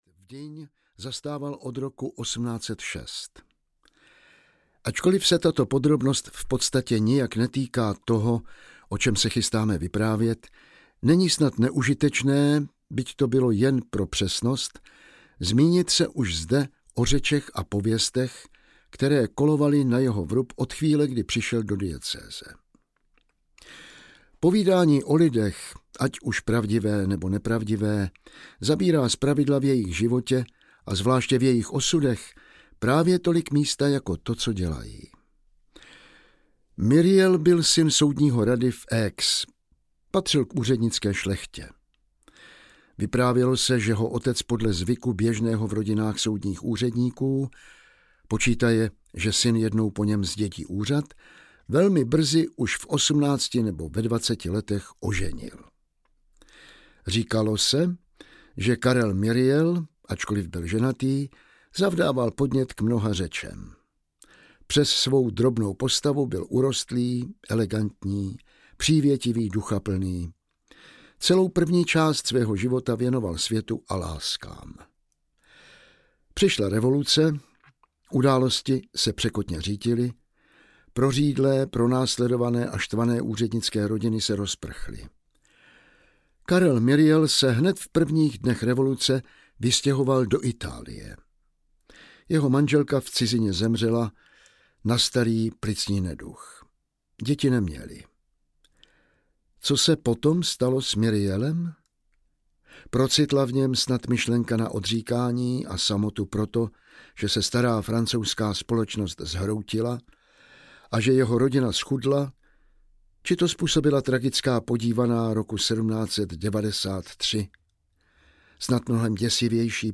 Bídníci audiokniha
Legendární román francouzského klasika v působivém podání Jana Vlasáka se stává srozumitelným průvodcem historií.
Ukázka z knihy
• InterpretJan Vlasák